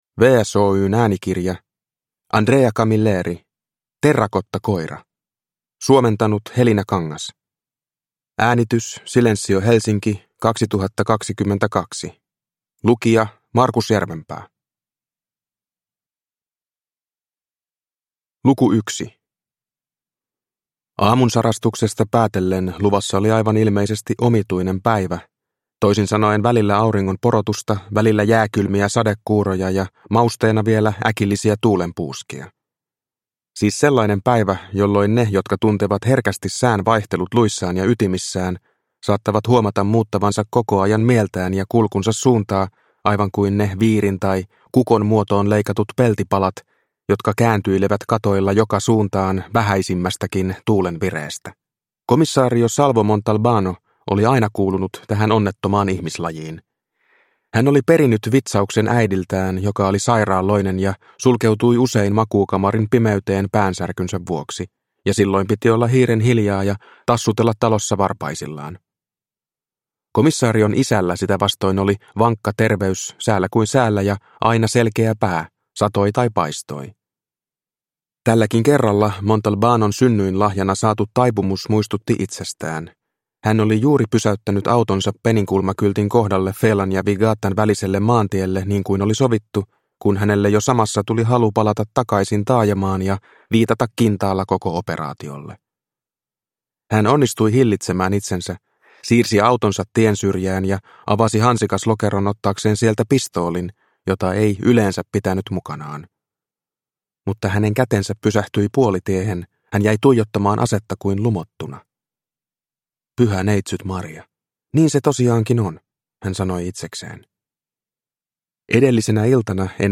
Terrakottakoira – Ljudbok – Laddas ner